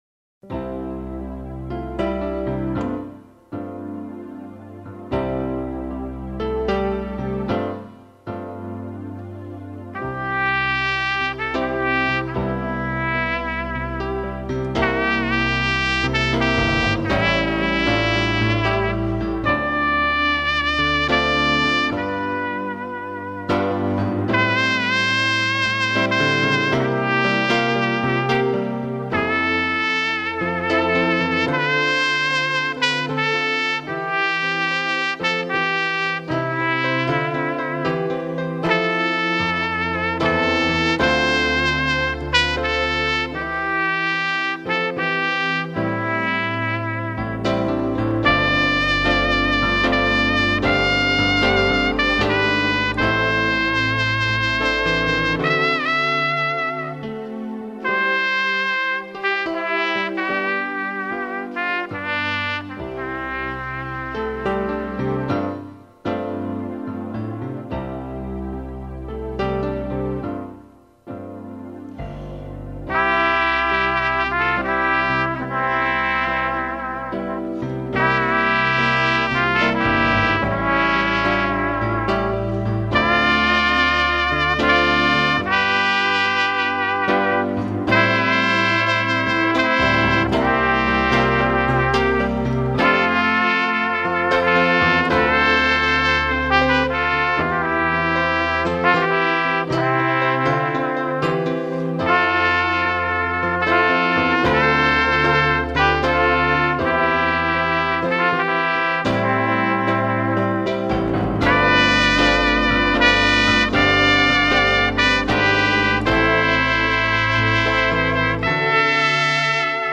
Silent night - piano and trumpet
Kategorie: Instrumental
Piano